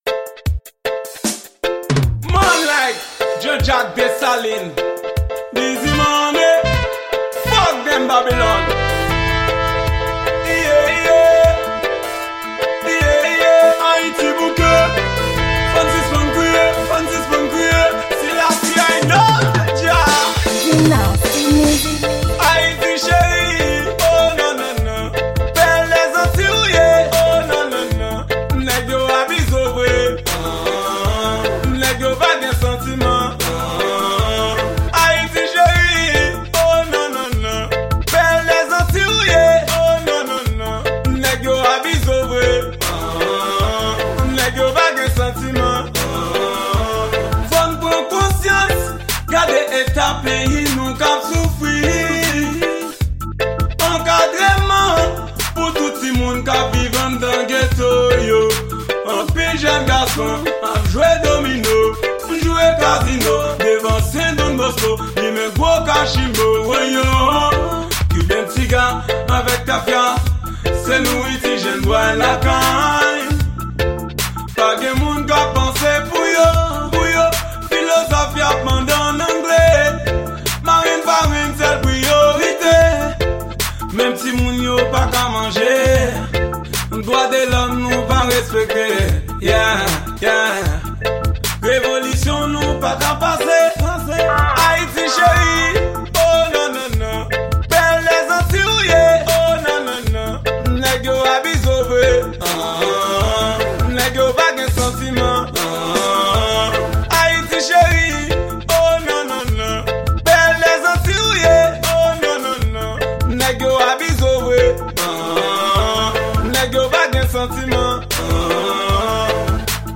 Genre: Raggae.